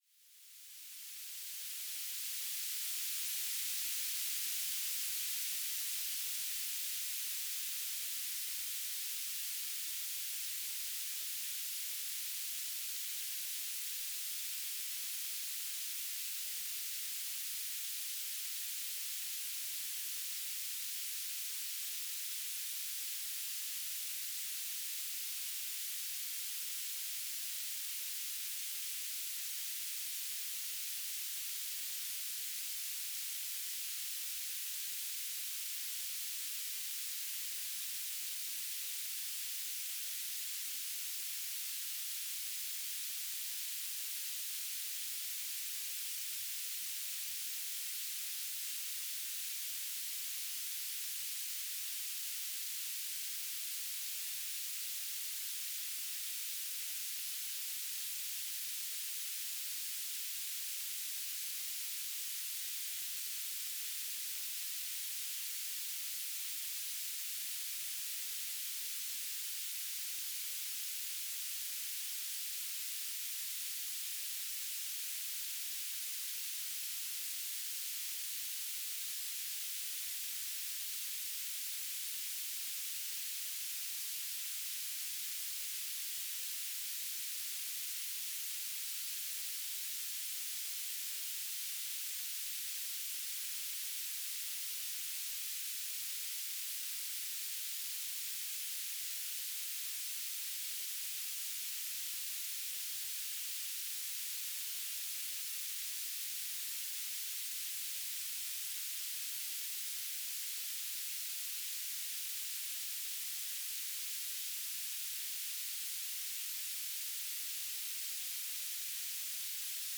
"transmitter_description": "S-band telemetry",
"transmitter_mode": "BPSK",